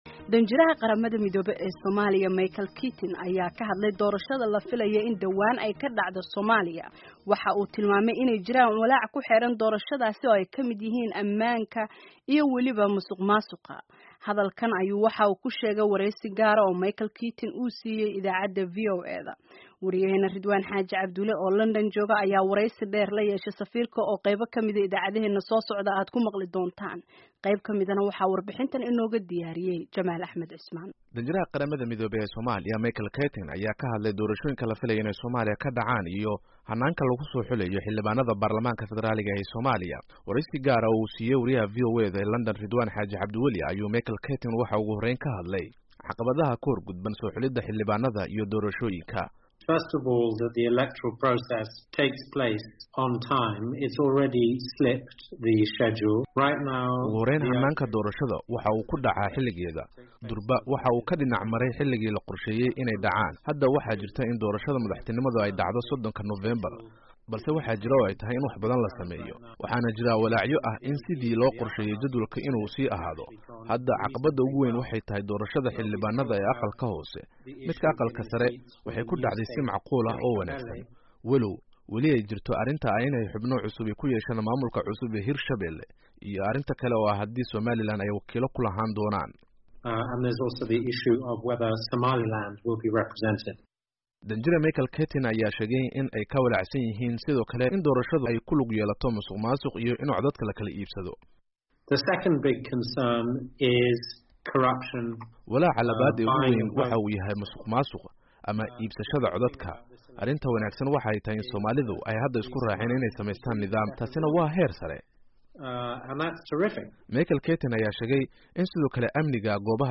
Dhagayso Keating oo ay VOA wareysatay